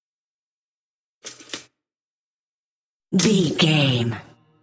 Bread toster
Sound Effects
foley